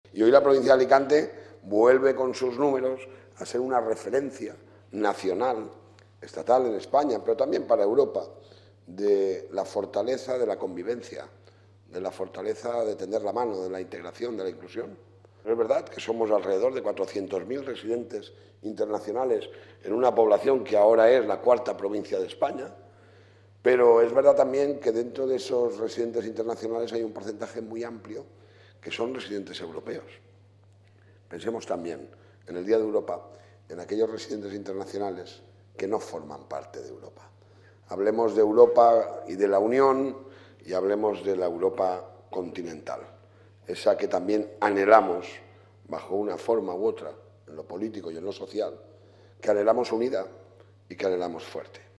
El presidente de la Diputación preside en el Palacio Provincial el acto conmemorativo del ‘Día de Europa’
Según ha trasladado el presidente en su alocución final, “la provincia de Alicante es una muestra de esa esencia aperturista e integradora que siempre nos ha caracterizado y que está intrínsecamente impregnada en la construcción de Europa”.
Dia-de-Europa.-Toni-Perez.mp3